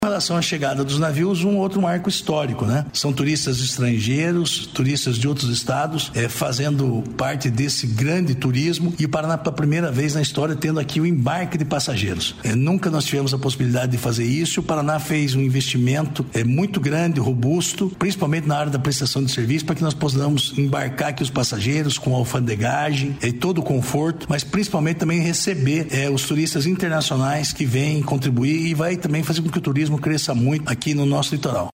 Sonora do secretário estadual do Turismo, Márcio Nunes, sobre as temporadas de cruzeiros MSC | Governo do Estado do Paraná